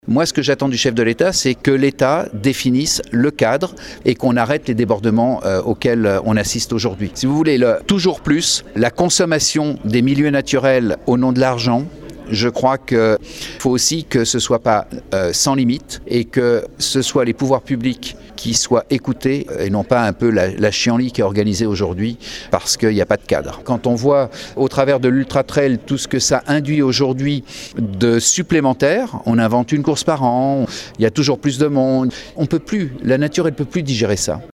On écoute Jean Marc Peillex, le maire de St Gervais.